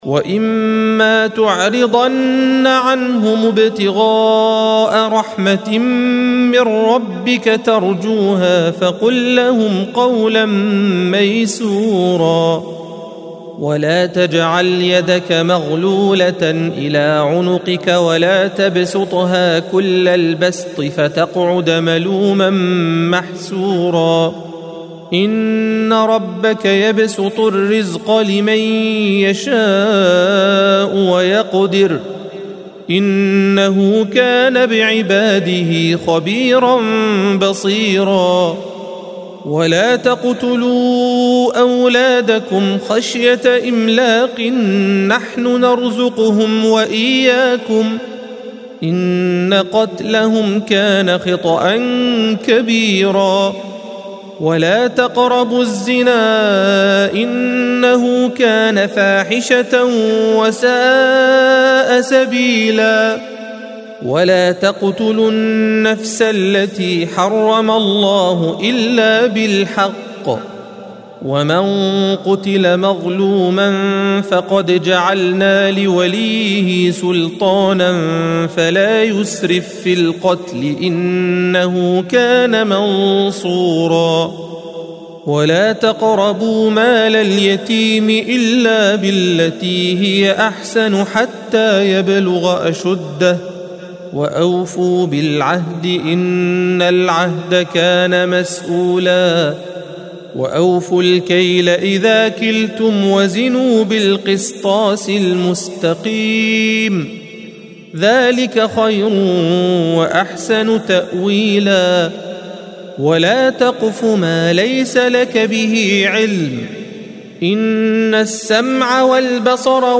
الصفحة 285 - القارئ